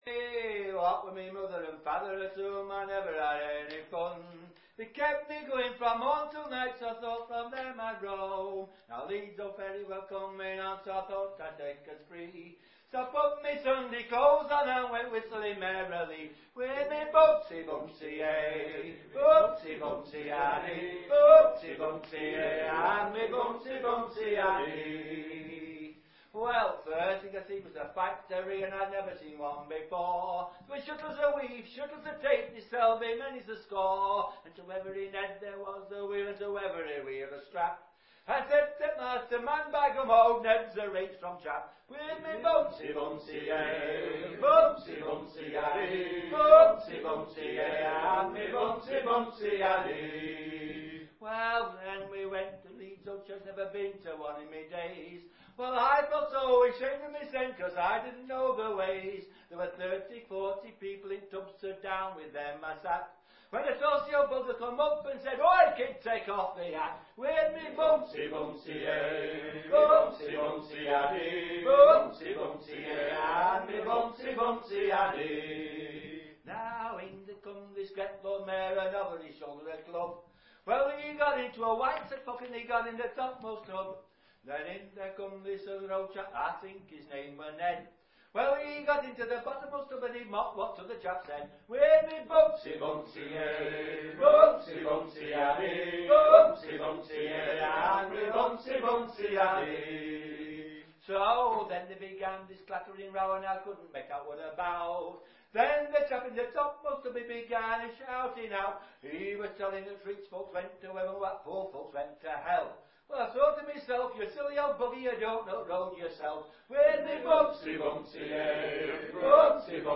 Morchard Bishop, Devon
Humour
Eb